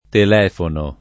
telefono [ tel $ fono ]